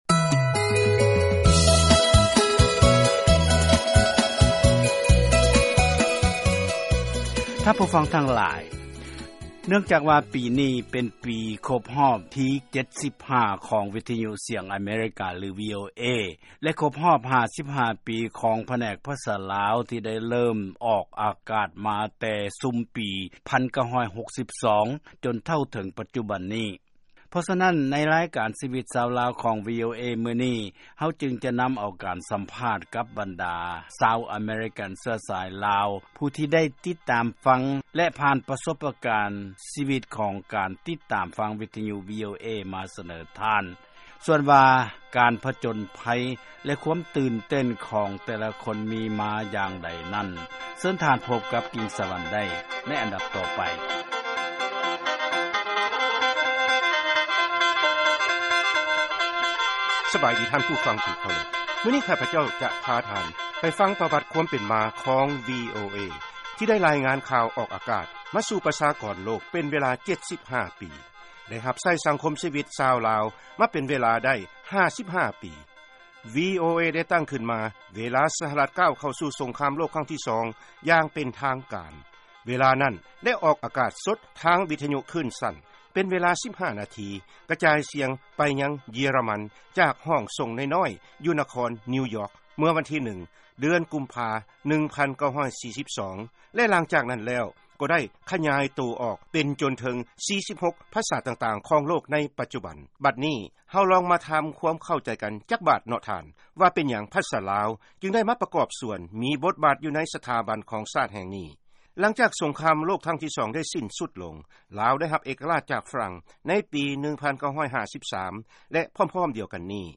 ເຊີນຟັງການສຳພາດ ບັນດາຜູ່ຕິດຕາມຟັງ ວີໂອເອ ສະຫລອງຄົບຮອບ 75 ປີ